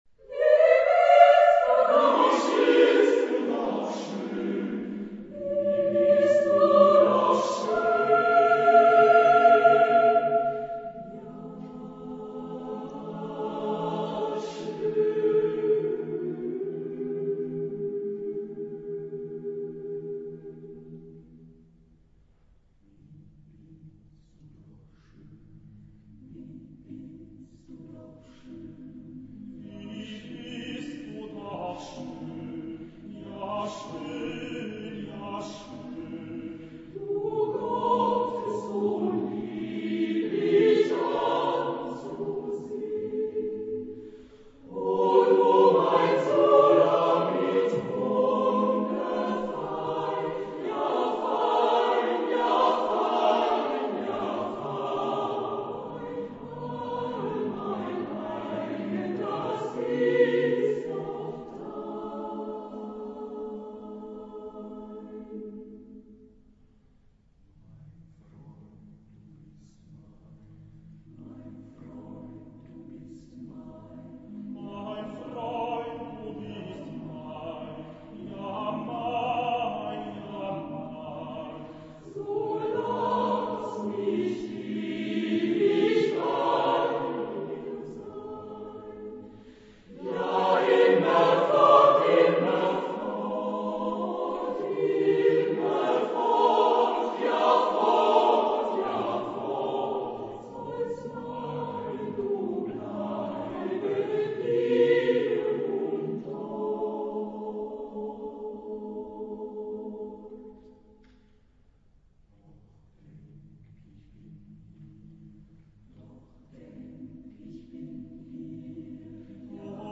Epoque: 19th century  (1850-1899)
Genre-Style-Form: Sacred ; Psalm ; Romantic
Type of Choir: SATB  (4 mixed voices )
Tonality: B flat major ; G minor ; A minor